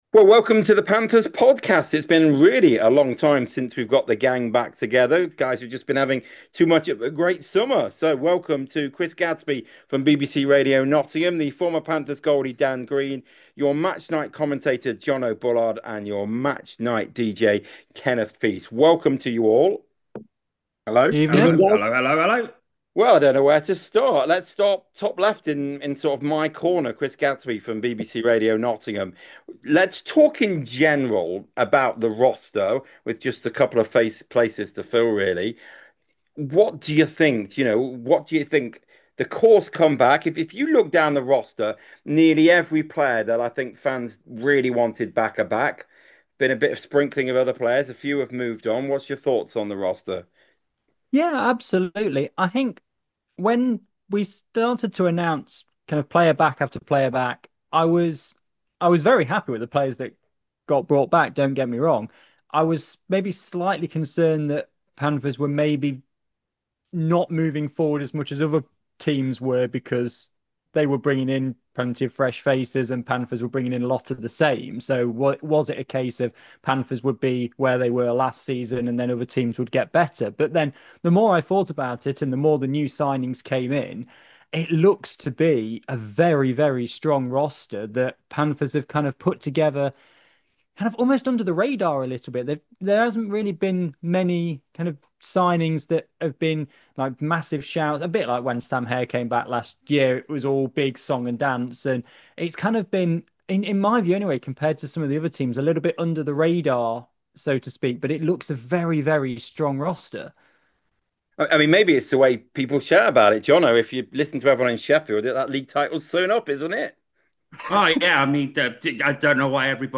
The five guys give their thoughts on the core of the team coming back, as well as reflections on the new additions.